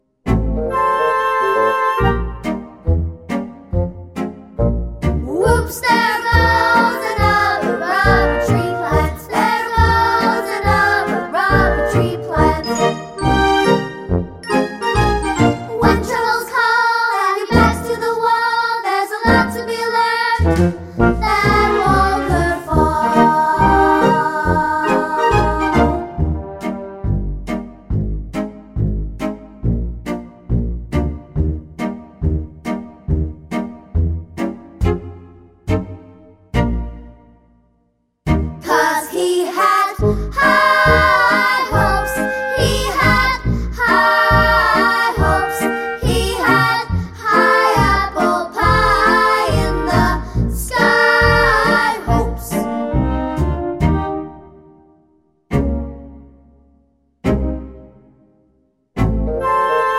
no Backing Vocals Jazz / Swing 2:40 Buy £1.50